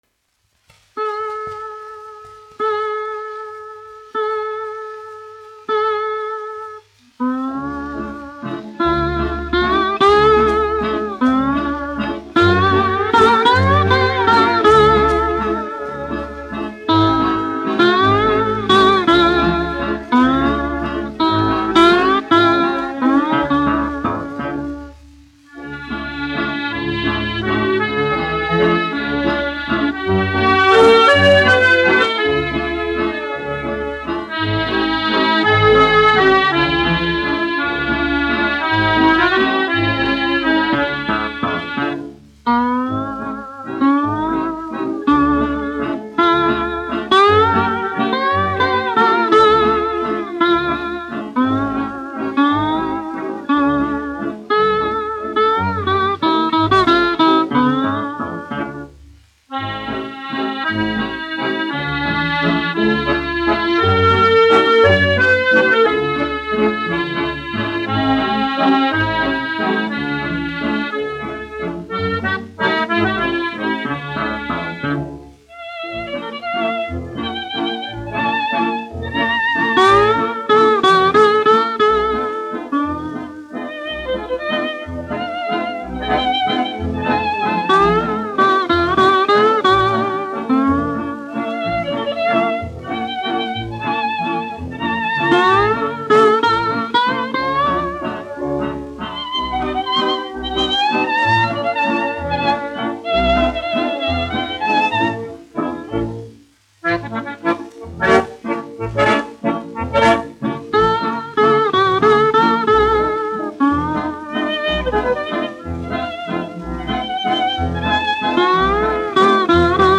1 skpl. : analogs, 78 apgr/min, mono ; 25 cm
Skaņuplate